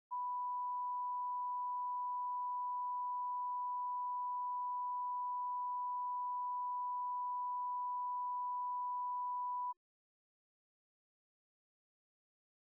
Recording Device: Oval Office
The Oval Office taping system captured this recording, which is known as Conversation 795-006 of the White House Tapes.
The President dictated a telegram.